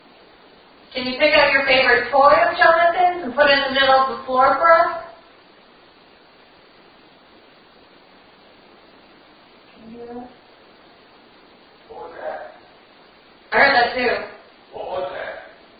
An unexplainable noise is capture on our audio device